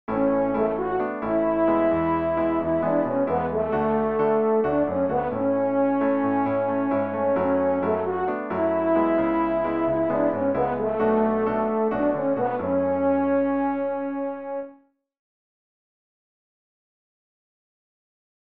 Here’s what that melody above sounds like when harmonized first with major chords, then using minor chords from the relative minor key:
The melody above actually harmonizes well by using the relative minor chord progression: F#m  C#m  Bm  F#m.
maj_min2.mp3